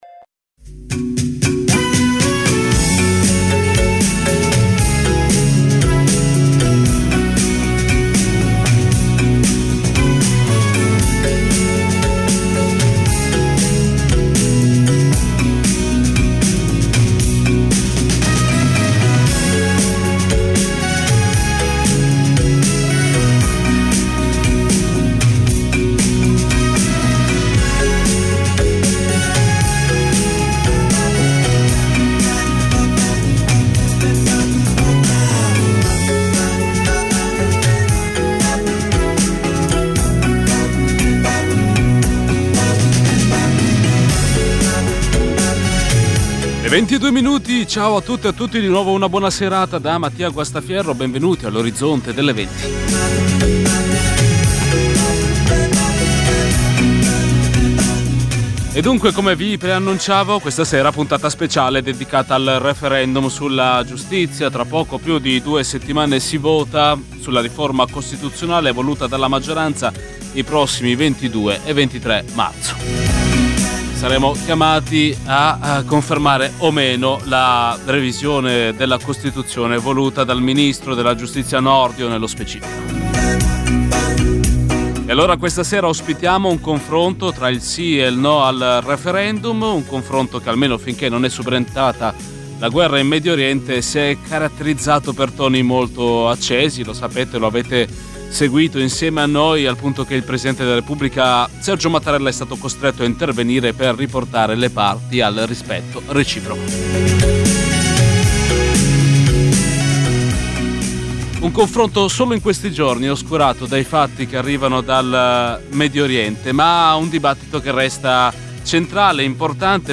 Meno due settimane al referendum sulla giustizia. Confronto tra il Sì e il No